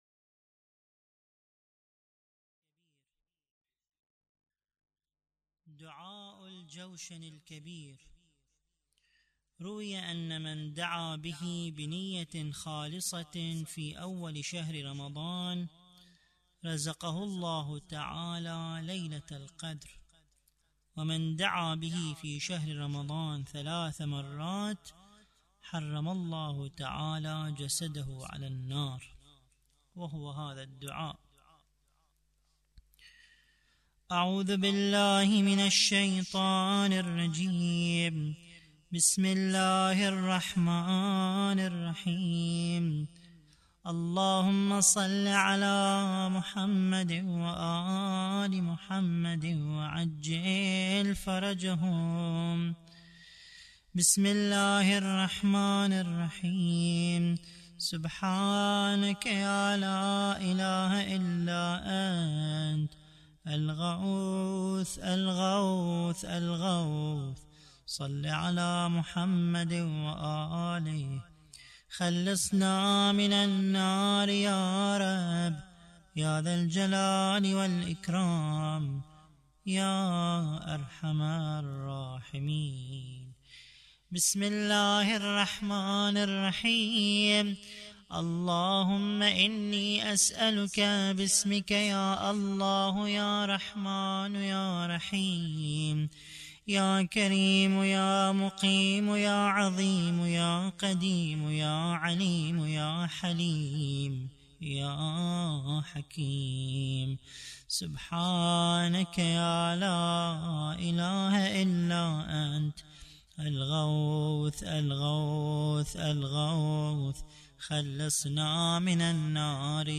دعاء الجوشن الكبير